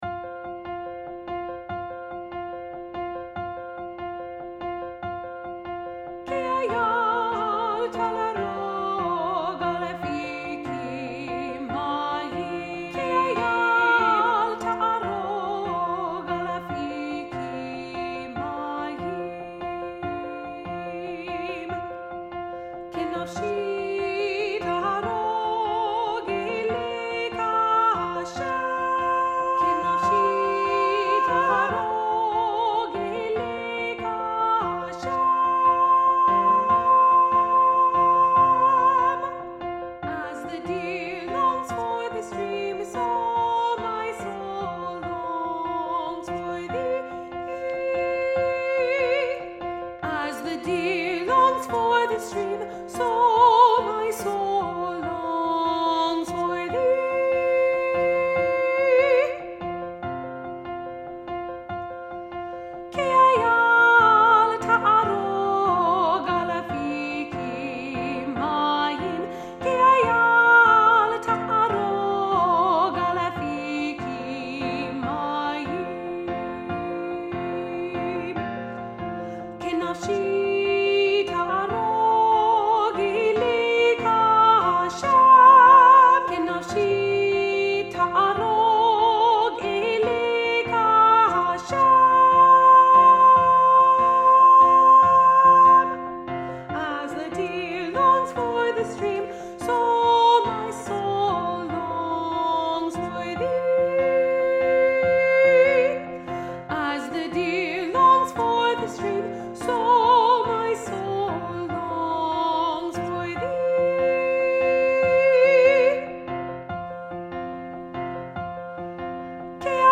Treble Choir Low